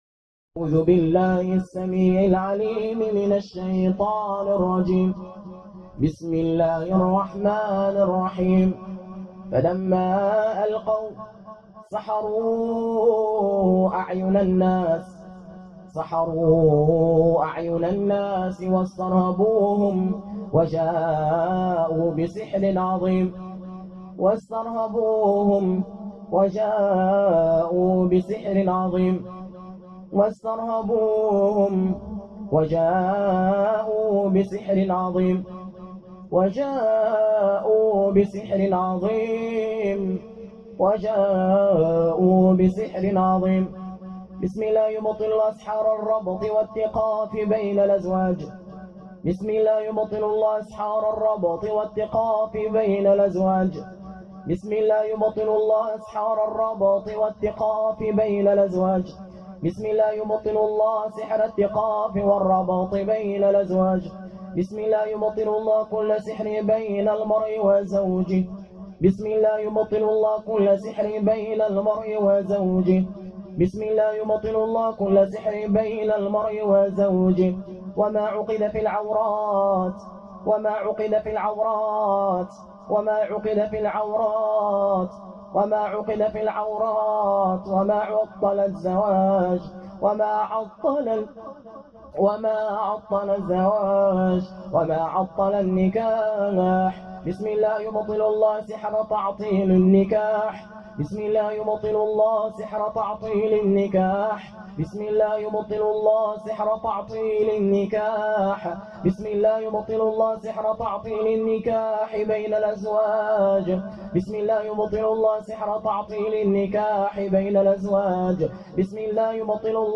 রুকইয়াহ অডিও